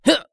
CK受伤1.wav
CK受伤1.wav 0:00.00 0:00.33 CK受伤1.wav WAV · 28 KB · 單聲道 (1ch) 下载文件 本站所有音效均采用 CC0 授权 ，可免费用于商业与个人项目，无需署名。
人声采集素材/男2刺客型/CK受伤1.wav